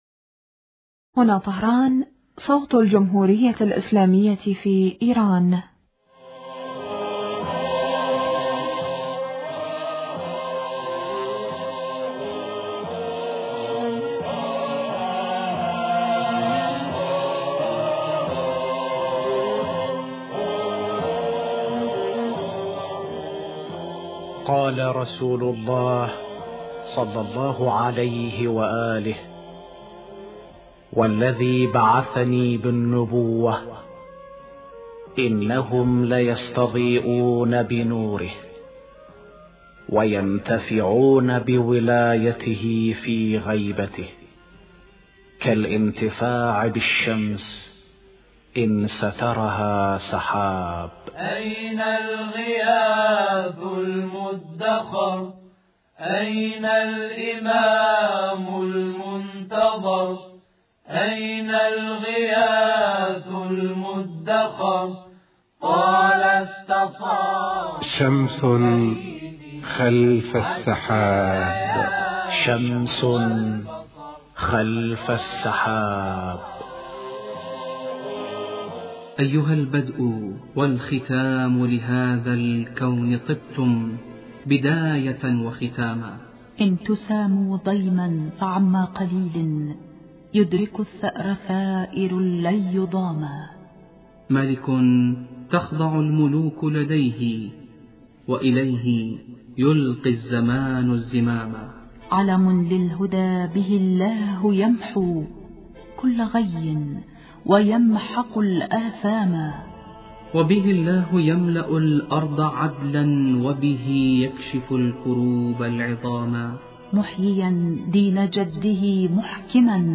تابعوا البرنامج من إذاعة طهران